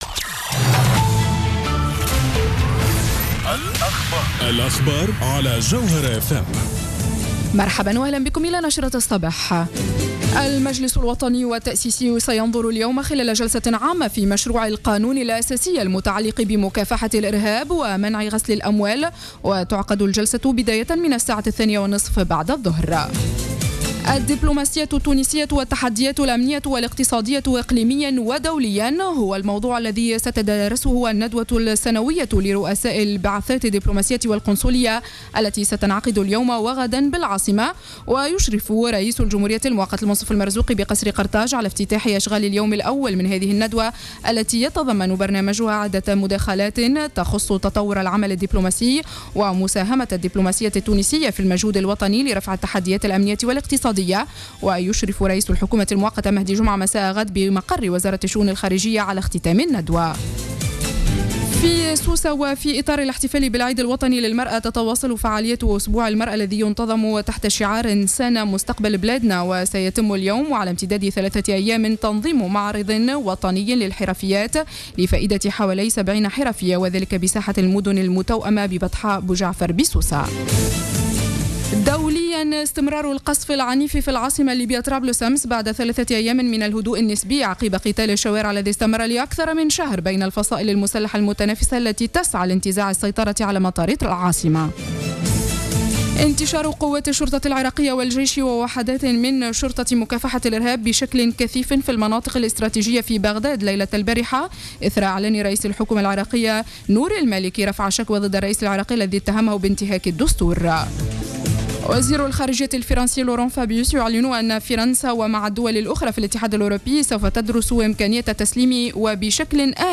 نشرة أخبار السابعة صباحا ليوم الإثنين 11-08-14